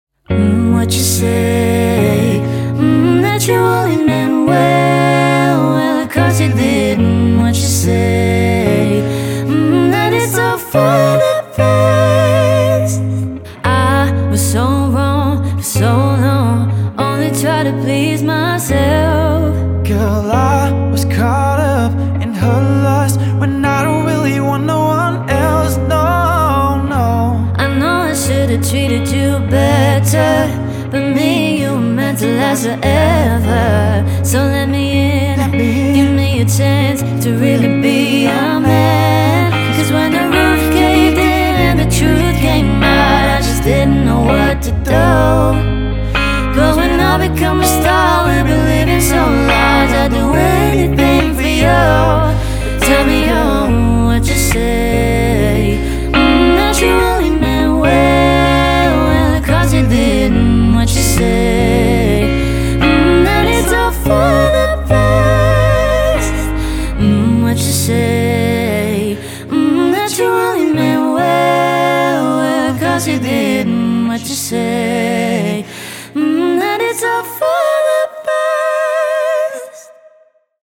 Dual Vocals | Guitar | Looping | Keys | DJ | MC